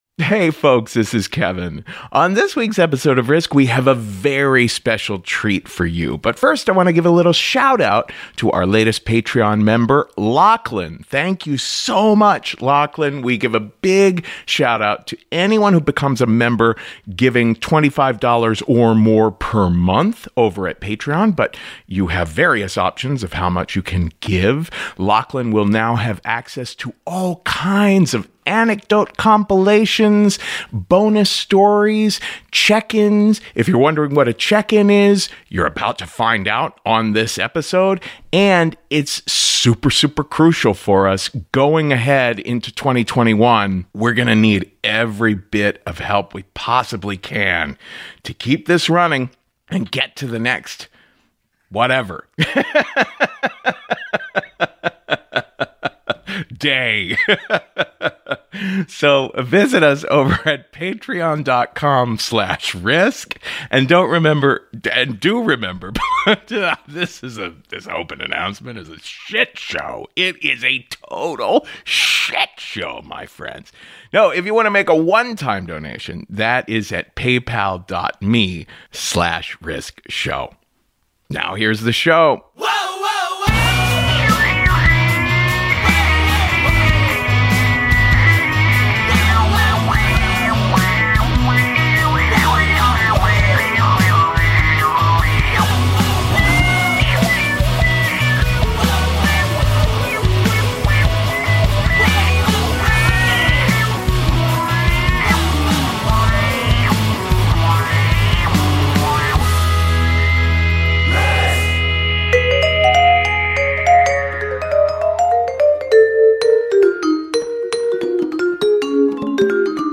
In this episode, several members of the staff do just that, about the year that was and where we’re at now.